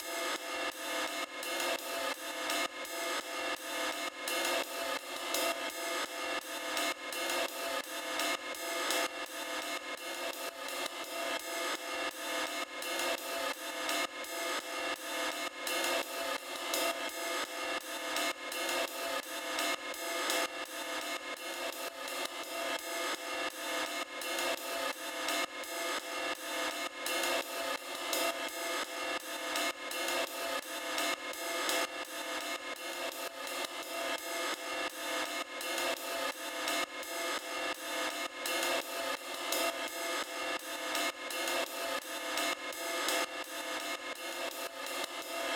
RIDELOOP_15.wav